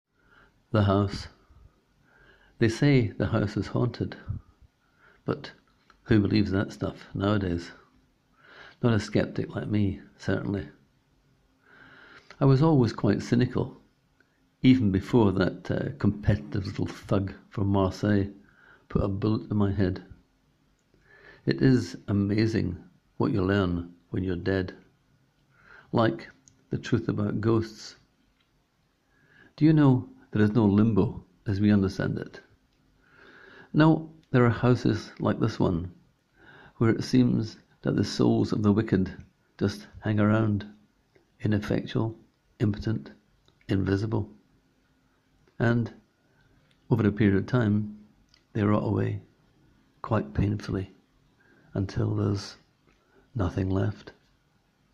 Click here to hear the author read his words:
I renjoyed your audio recording: it gave just that extra bit authenticity to the ghost persona 🙂
You somehow imbued it with menace, disdain and out-and-out hatred.